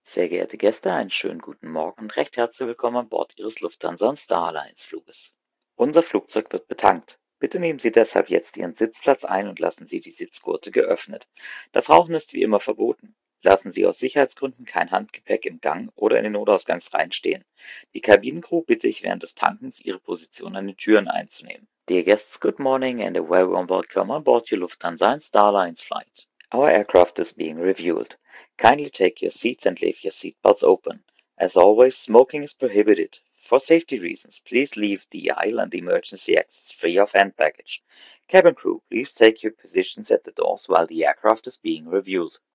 BoardingWelcome[Refueling][Morning].ogg